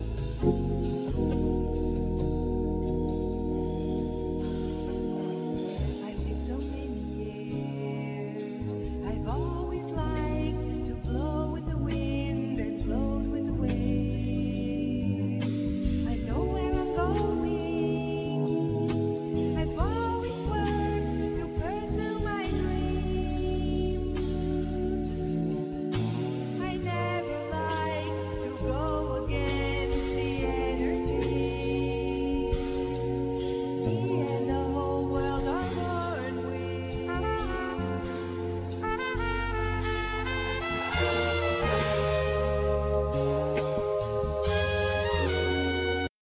Trumpet
Piano & Keyboard
Basses
Percussion,Drums,Voice